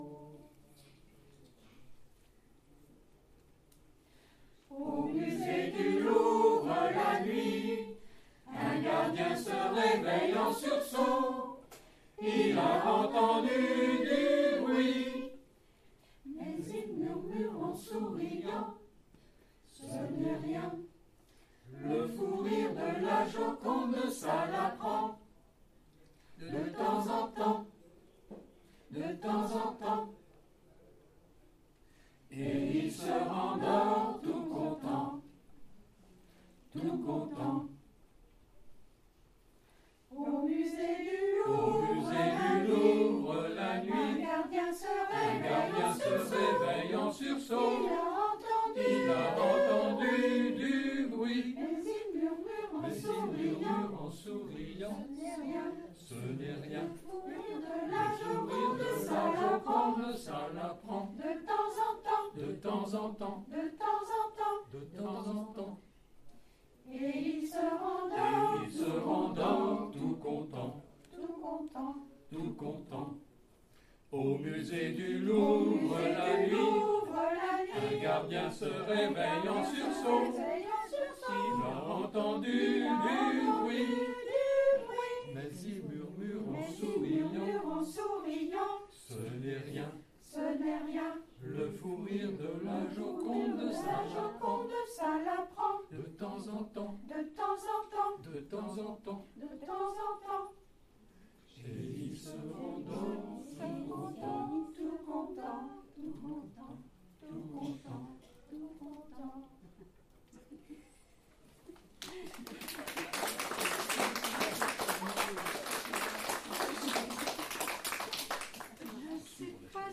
Cess'tival 2025 le 21 juin à Cesson-Sévigné à la médiathèque du Pont des Arts